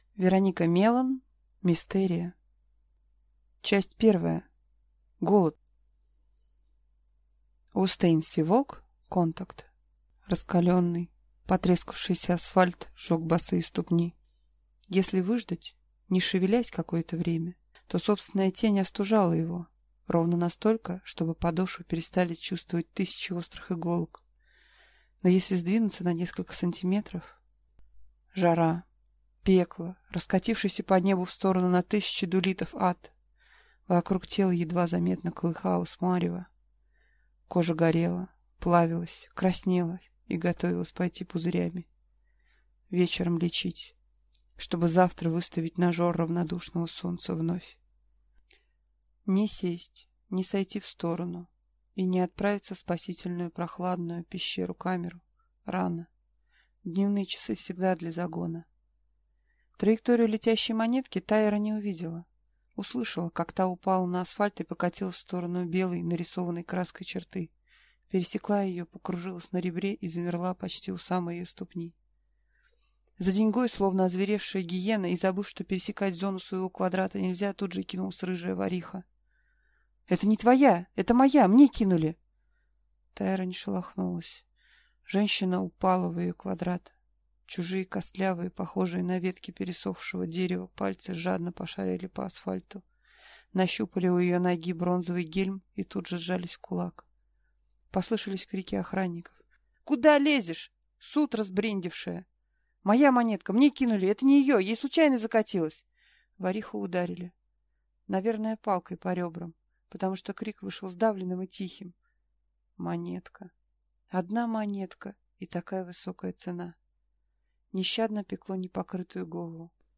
Аудиокнига Мистерия - купить, скачать и слушать онлайн | КнигоПоиск